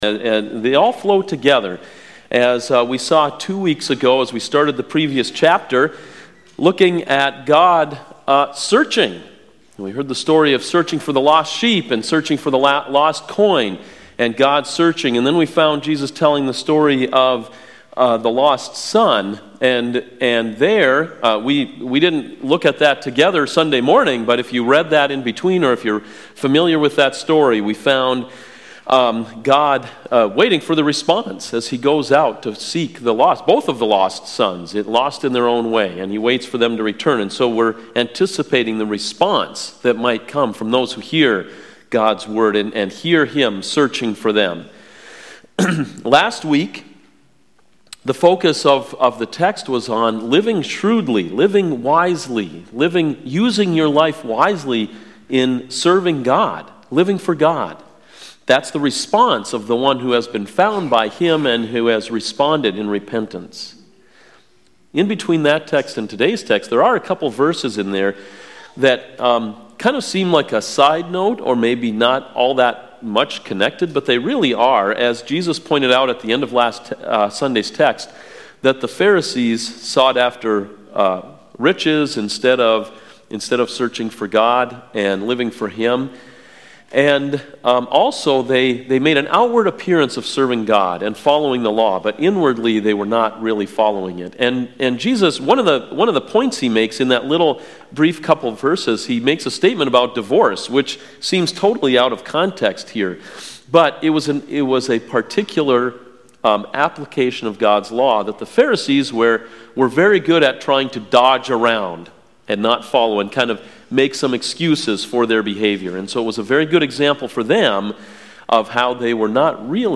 CoJ Sermons